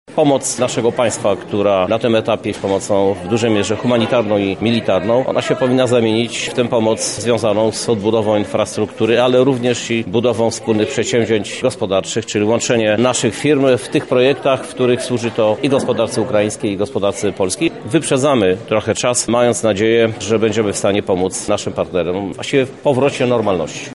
• mówi prezydent Żuk.